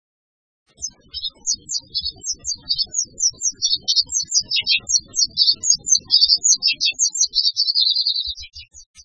〔コヨシキリ〕ジュジュッ／ピッピピピケケシ（さえずり）／山地以下の草原や湿原に